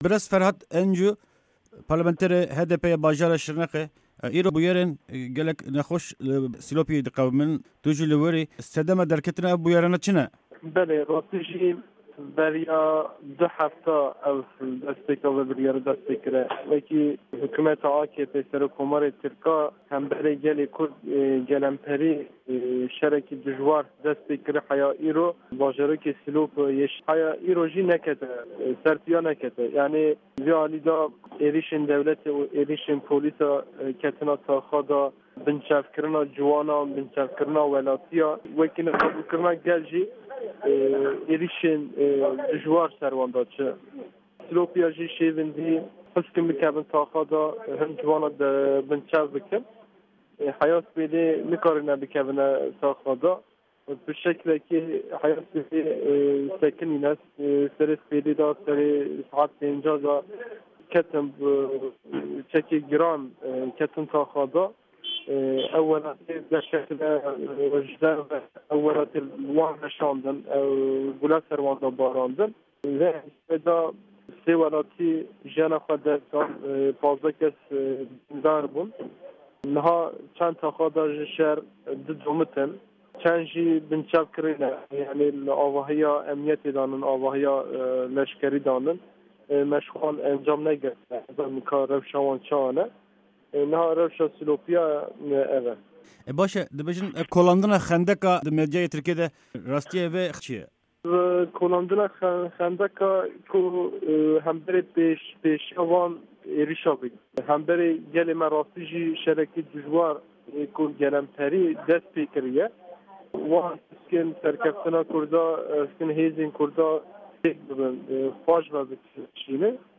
Parlamanterê HDPê yê bajarê Şirnêxê Ferhat encu di vê hevpeyvînê de agahîyên herî dawî ji herêmê dide.